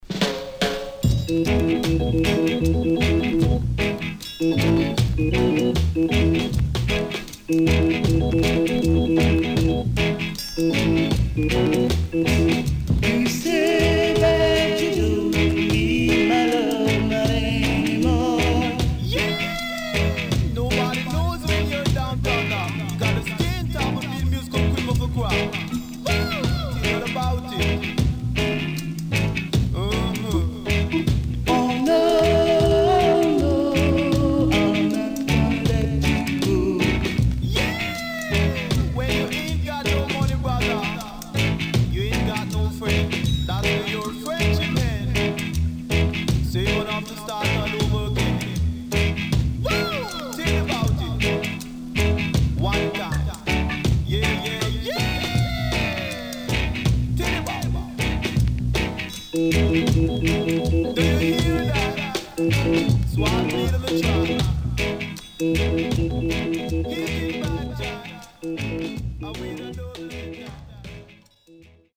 Old School Deejay on Foundation Track.Pressnoise
SIDE A:少しプレスノイズ入ります。